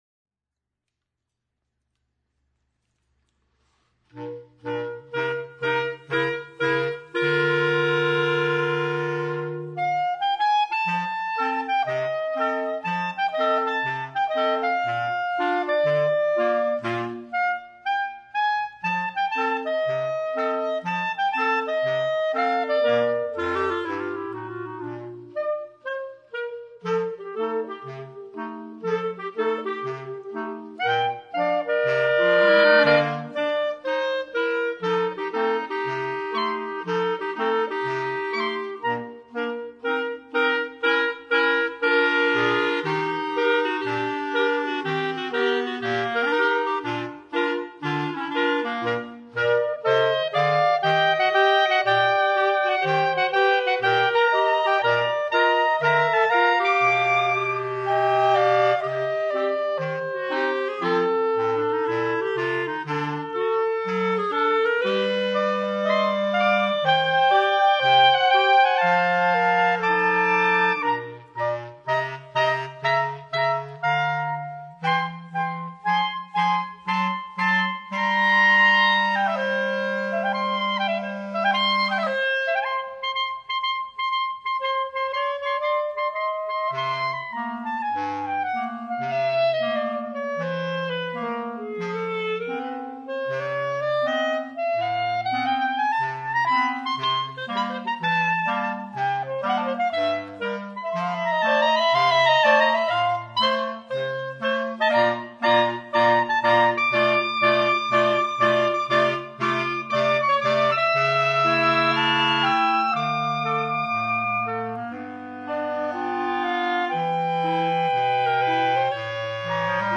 per quintetto di clarinetti